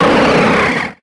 Grito de Relicanth.ogg
Grito_de_Relicanth.ogg.mp3